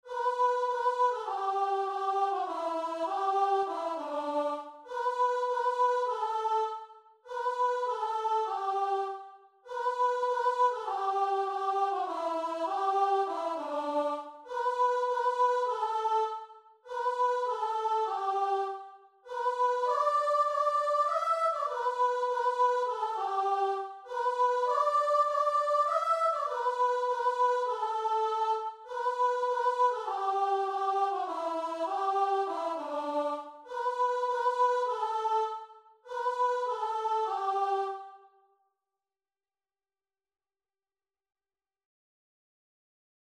Christian
4/4 (View more 4/4 Music)
Guitar and Vocal  (View more Easy Guitar and Vocal Music)
Classical (View more Classical Guitar and Vocal Music)